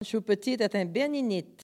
Enquête Arexcpo en Vendée-Lucus
Catégorie Locution